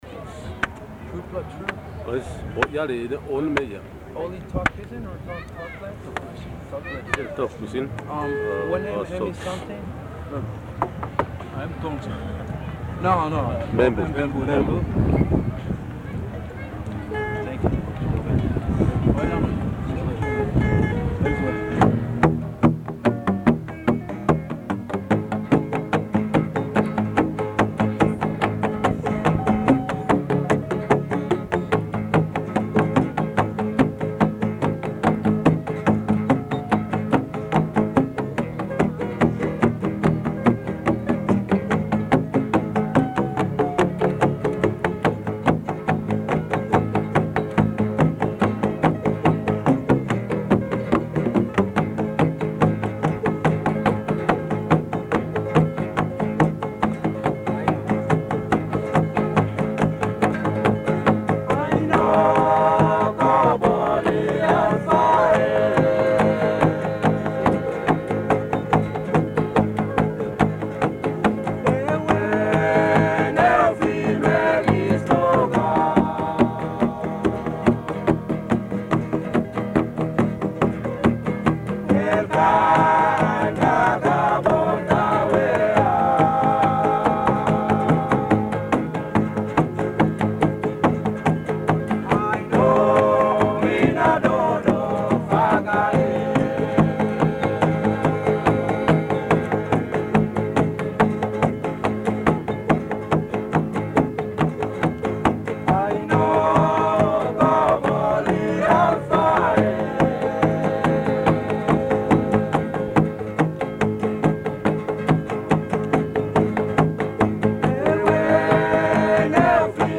I captured some of their music on my walkman-like device, including my talking to them in my attempt at Tok Pisin (the pidgin English spoken in New Guinea.)
At the beginning of this track (01002) you can hear me say it was "gutpela tru" (very good / "good fella true") and ask them if they speak Tok Pisin.
men playing music on bamboo pipes with thongs (flip-flops) at Goroka Highlands show, Papua New Guinea, 1984 men playing music on bamboo pipes with thongs (flip-flops) at Goroka Highlands show, Papua New Guinea, 1984